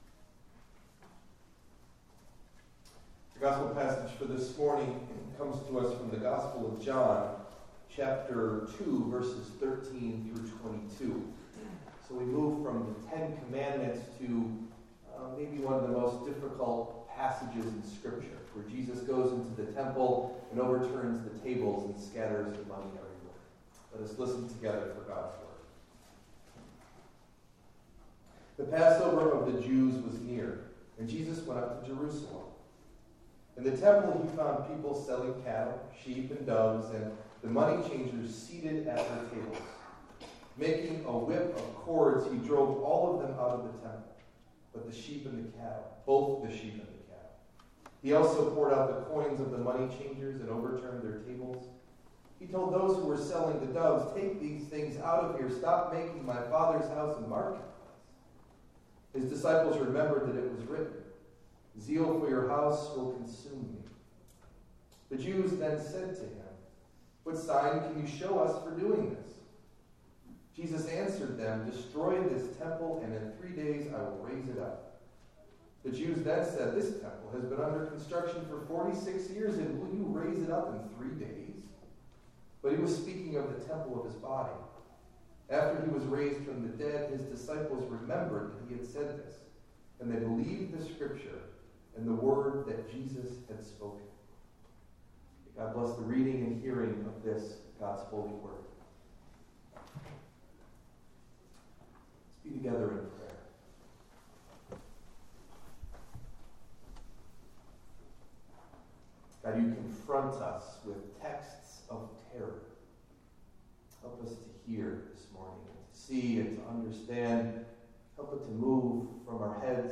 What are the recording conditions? Sermon Delivered at: The United Church of Underhill (UCC and UMC)